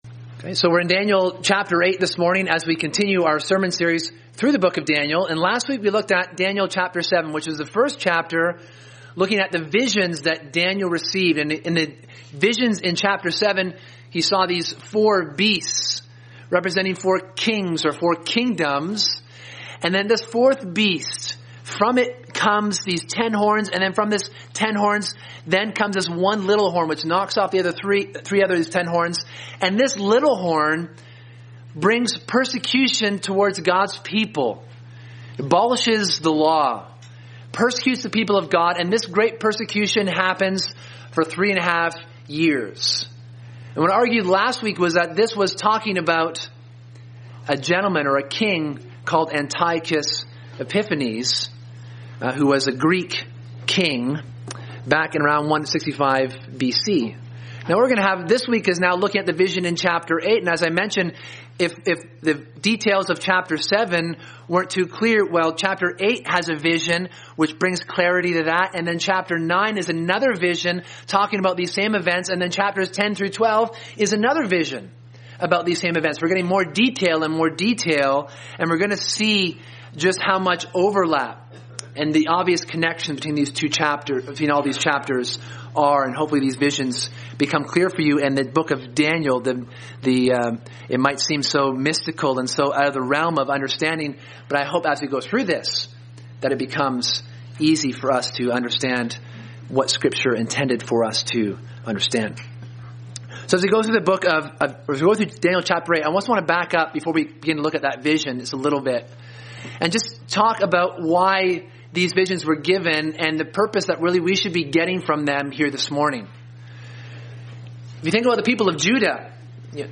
Sermon: The Vision of the Ram and Goat